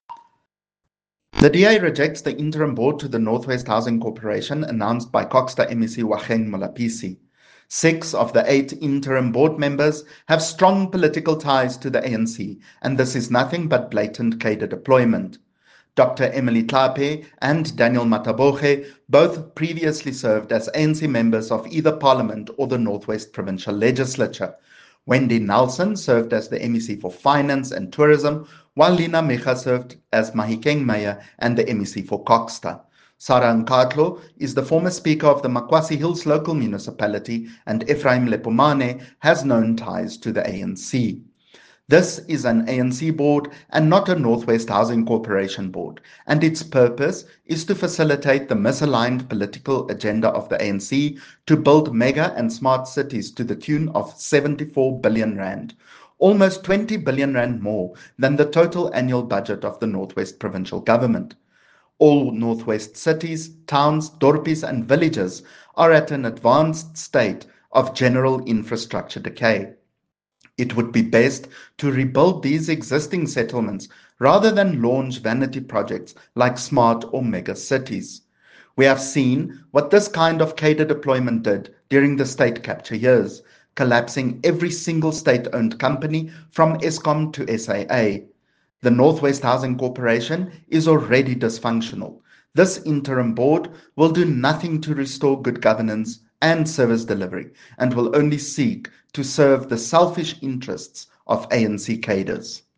Note to Broadcasters: Please find linked soundbites in
Afrikaans by CJ Steyl MPL.